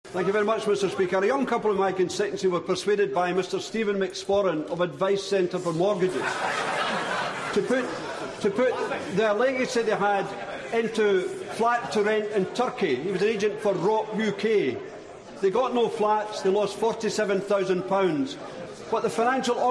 PMQs, 25 March 2015.